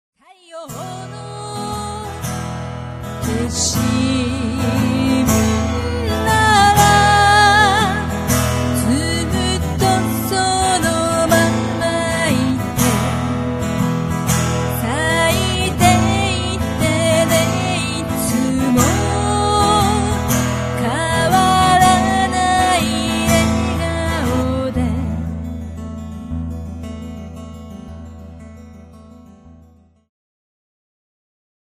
ジャンル アコースティック
ボーカルフューチュア
癒し系